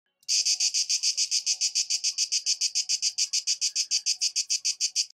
Enregistrement des cymbalisations du mâle Cidada orni.